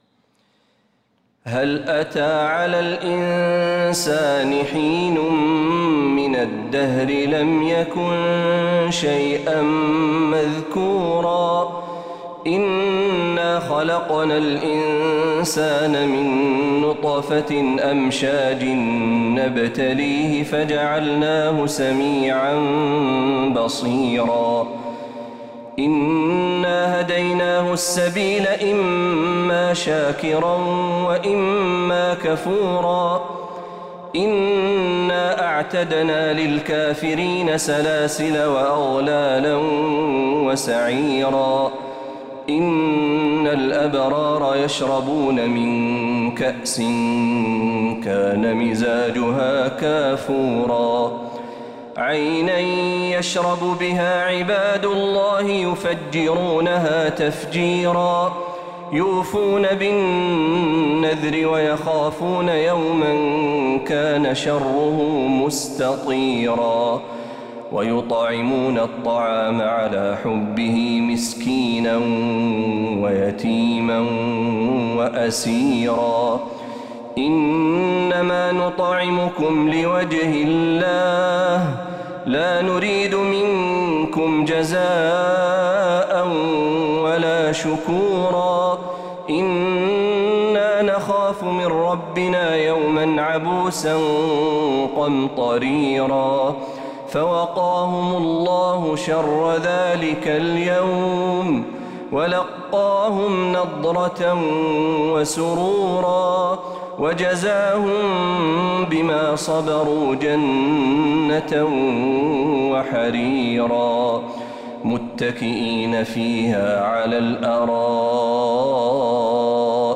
سورة الإنسان كاملة من فجريات الحرم النبوي للشيخ محمد برهجي | ربيع الآخر 1446هـ > السور المكتملة للشيخ محمد برهجي من الحرم النبوي 🕌 > السور المكتملة 🕌 > المزيد - تلاوات الحرمين